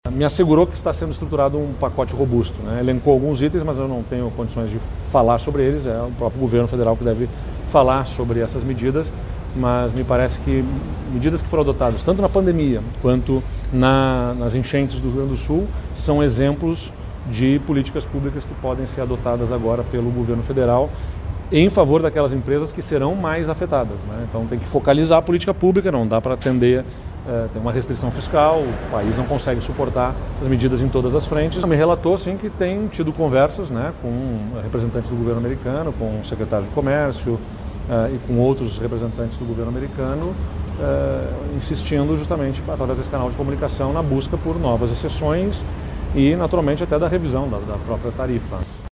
O governador comentou que há perspectiva de pacote de ajuda para as empresas gaúchas afetadas pelo aumento de tarifa, segundo o que pôde ouvir de Geraldo Alckmin. (Abaixo, manifestação de Eduardo Leite).
01.-SONORA-EDUARDO-LEITE.mp3